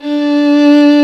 Index of /90_sSampleCDs/Roland - String Master Series/STR_Violin 1-3vb/STR_Vln3 % + dyn
STR VLN3 D 3.wav